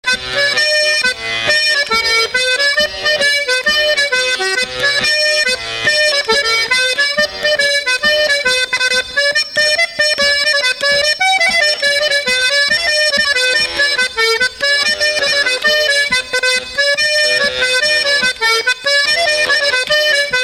Résumé instrumental
danse : bal paludier
Pièce musicale inédite